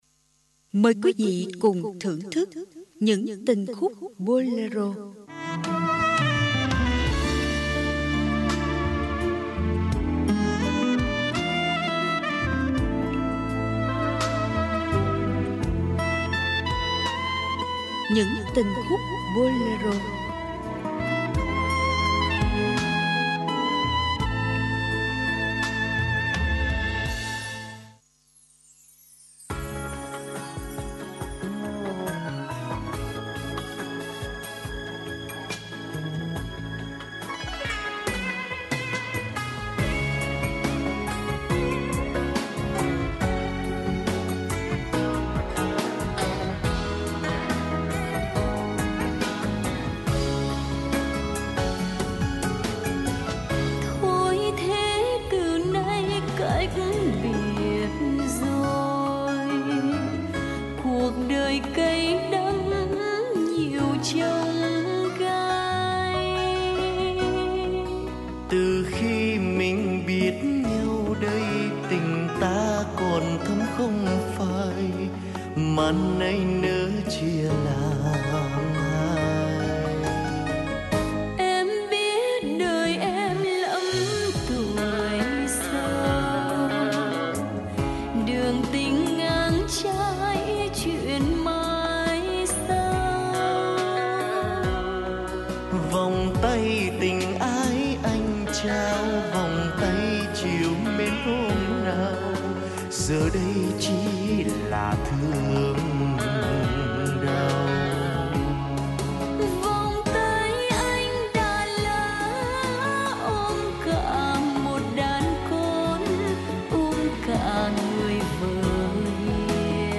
Ca nhạc Radio